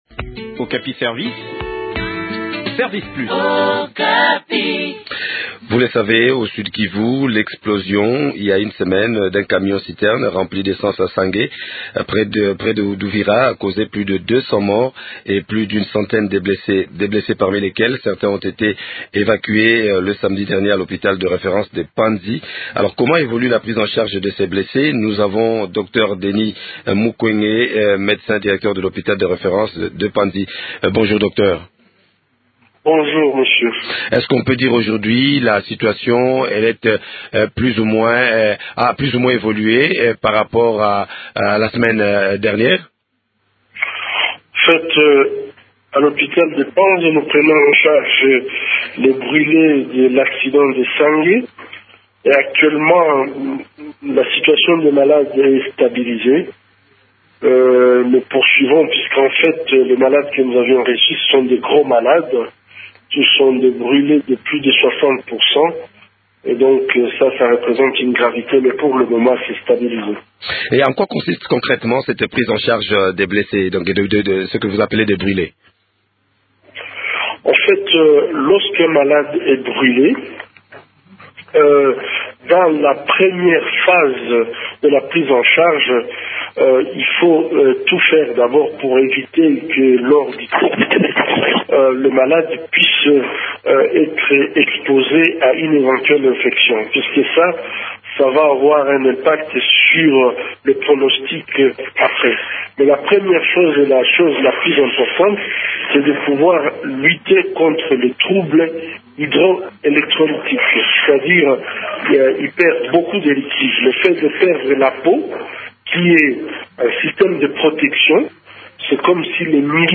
fait le point de la situation avec Denis Mukwege, médecin directeur à l’hôpital général de Panzi.